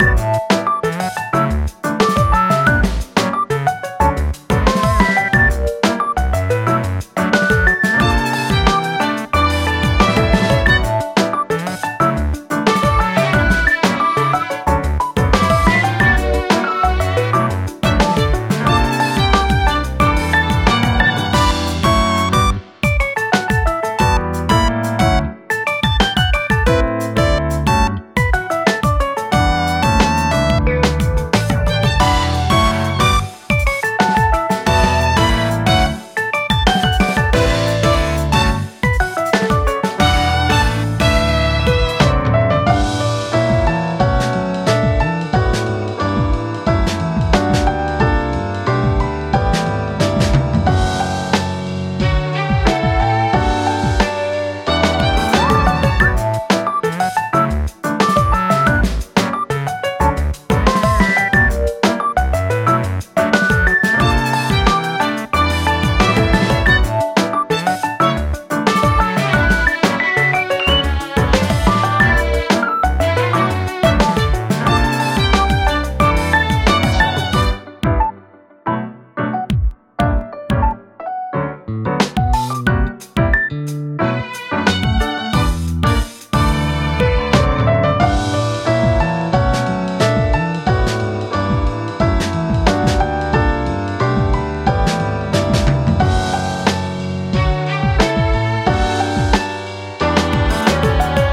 ループ用音源（BPM=90）
ループ本体
• ループ本体 隙間なく繋げていくと無限にループできるように編集した音源。